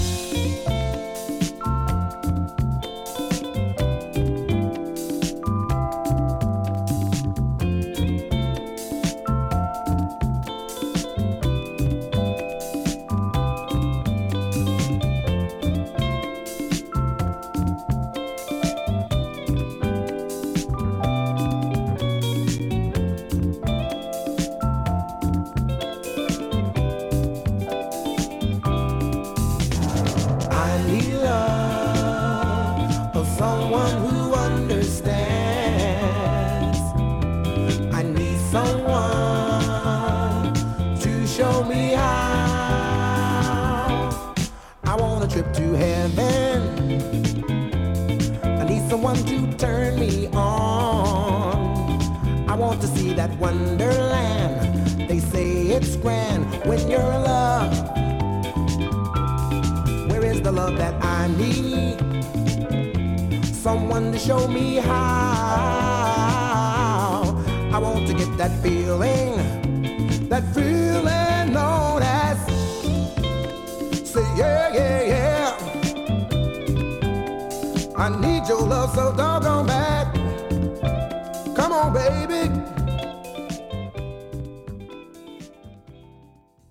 もはや何も書くことはない聴いて卒倒するグルーヴ。